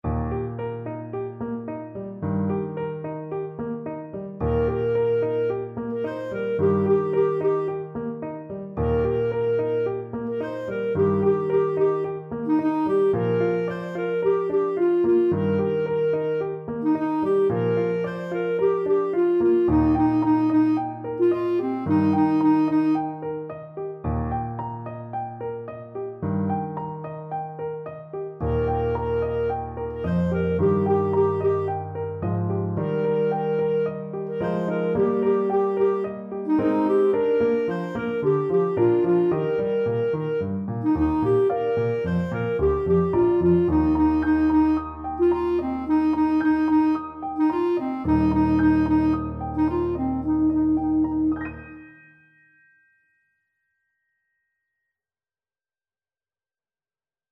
Clarinet
2/4 (View more 2/4 Music)
Eb major (Sounding Pitch) F major (Clarinet in Bb) (View more Eb major Music for Clarinet )
Allegro moderato =c.110 (View more music marked Allegro)
Traditional (View more Traditional Clarinet Music)
world (View more world Clarinet Music)